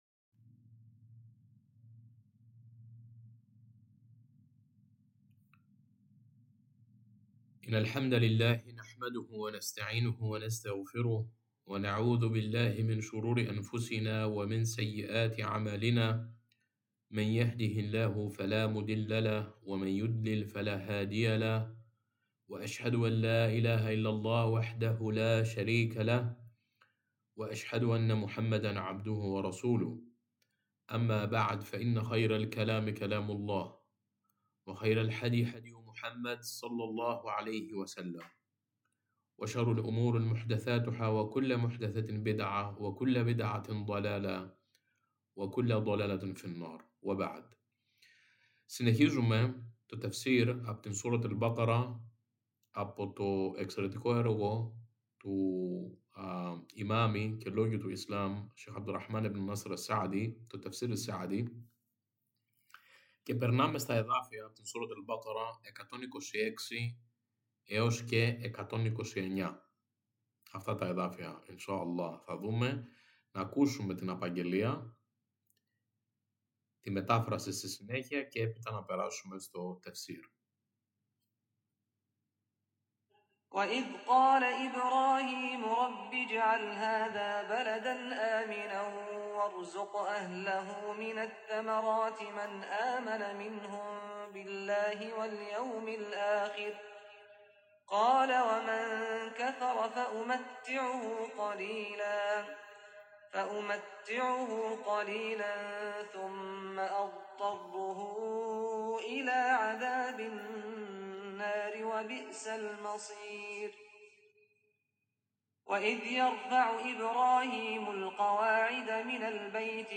Ανάγνωση, Μετάφραση και Παρουσίαση